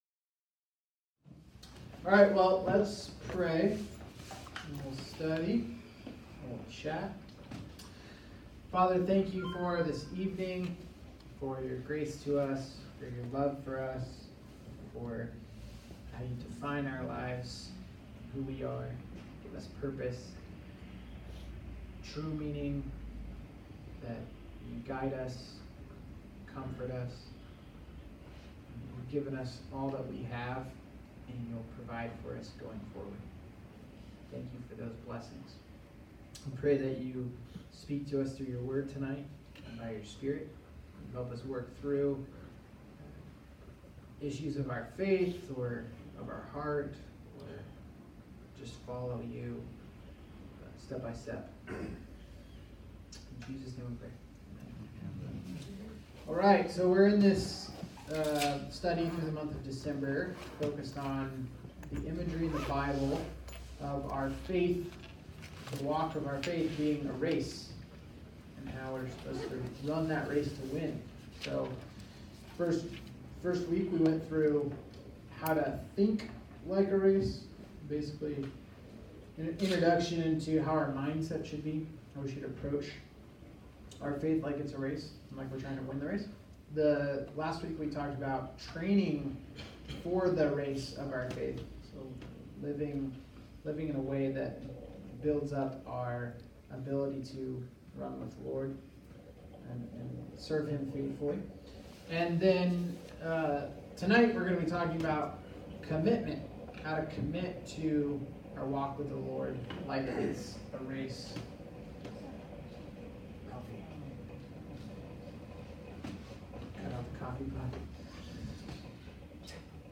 All Sermons How to commit like a Race December 20